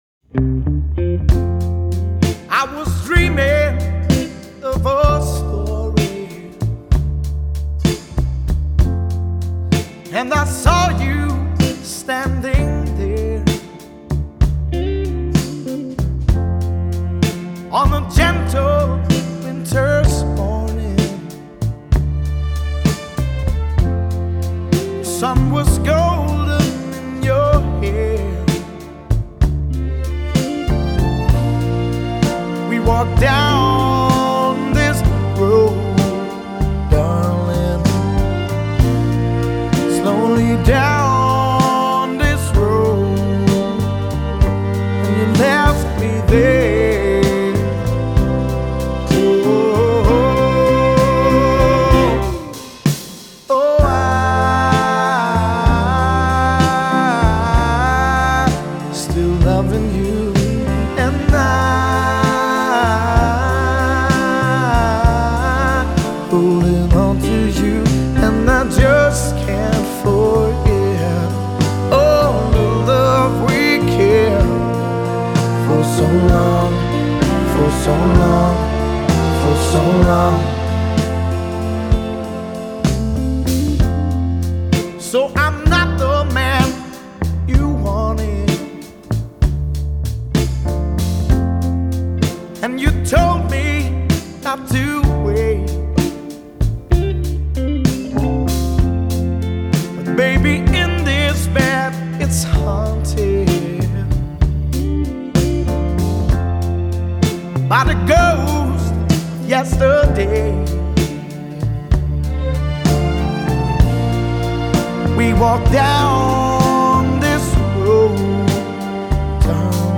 Genre: Pop, Rock, Blues